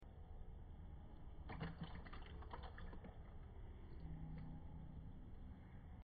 sounds from a café…. strawberry sound effects free download